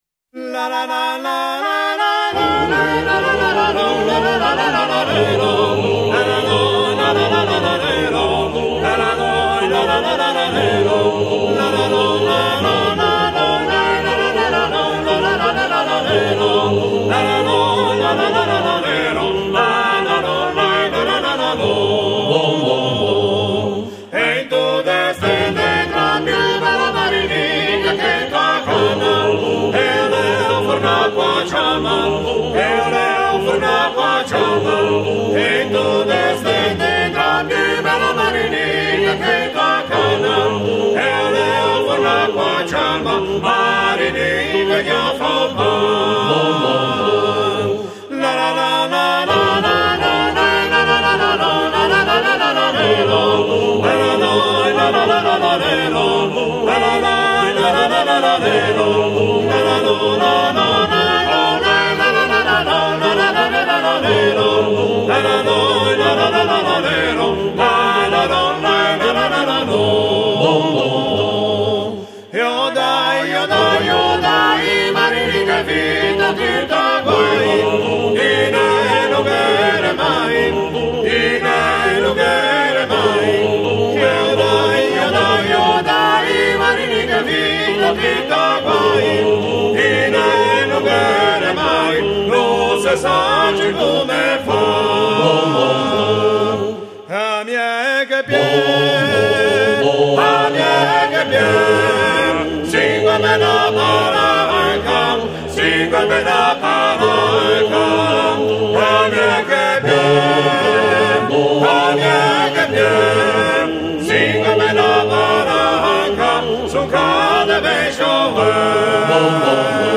Tralalêro zenéize
Séntila cantâ da-i Canterini della Val Bisagno ] [ Tèsto e acòrdi in pdf ] [ Dìnni a teu ]